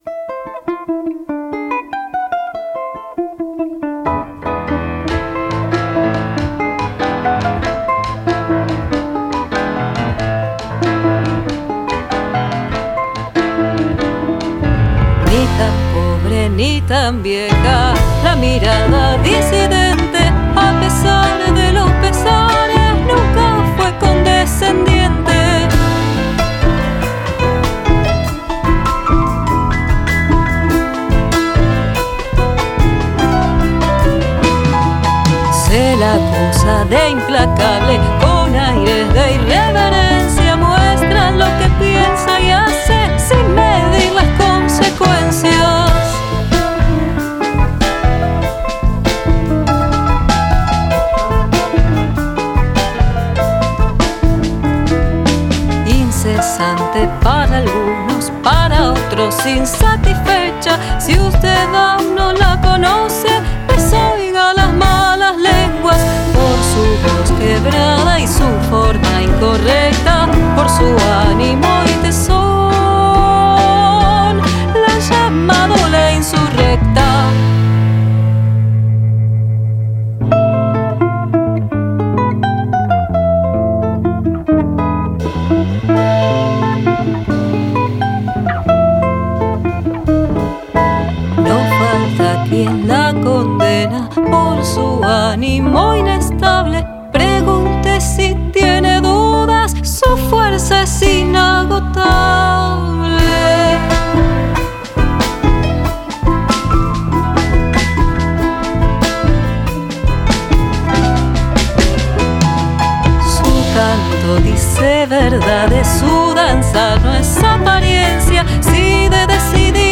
Voz           –          Piano       –         Percusión
Con una estética fuerte, arreglos y orquestación originales.